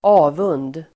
Uttal: [²'a:vun:d]